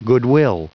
Prononciation du mot goodwill en anglais (fichier audio)
Prononciation du mot : goodwill